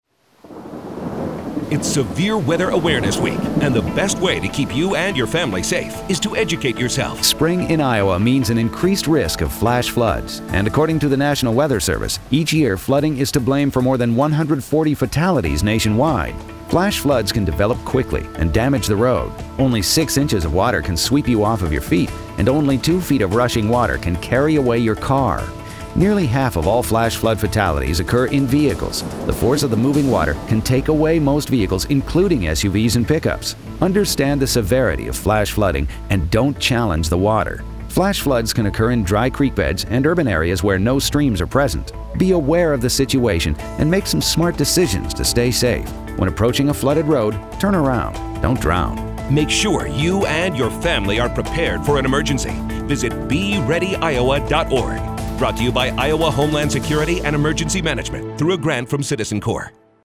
PSA_SWAW_FlashFloods.mp3